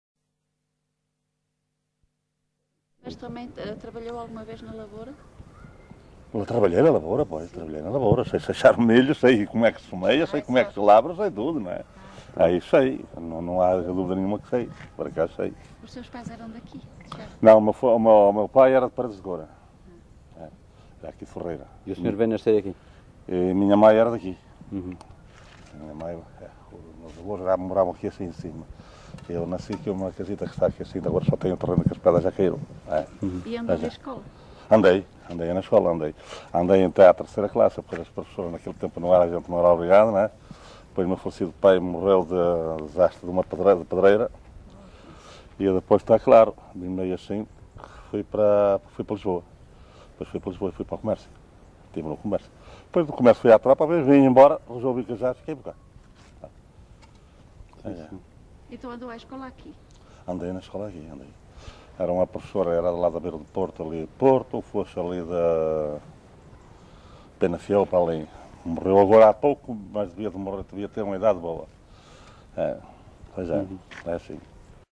LocalidadeBade (Valença, Viana do Castelo)